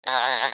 دانلود صدای حشره 4 از ساعد نیوز با لینک مستقیم و کیفیت بالا
جلوه های صوتی
برچسب: دانلود آهنگ های افکت صوتی انسان و موجودات زنده دانلود آلبوم صدای انواع حشرات از افکت صوتی انسان و موجودات زنده